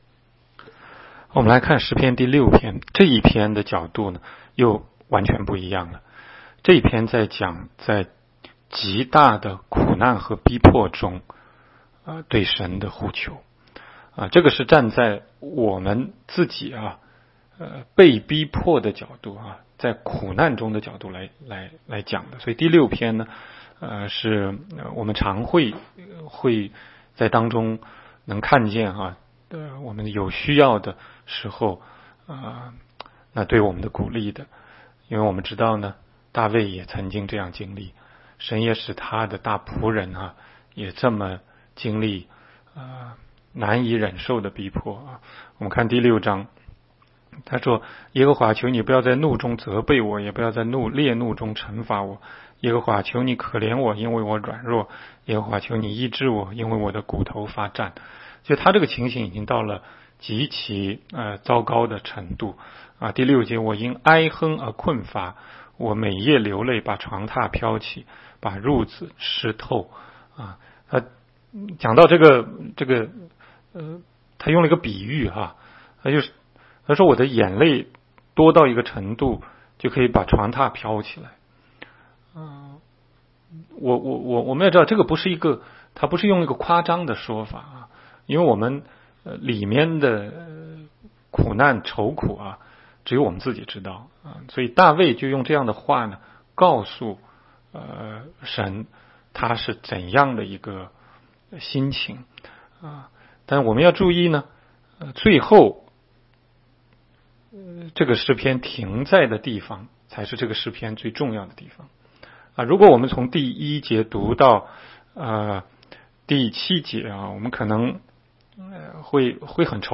16街讲道录音 - 每日读经-《诗篇》6章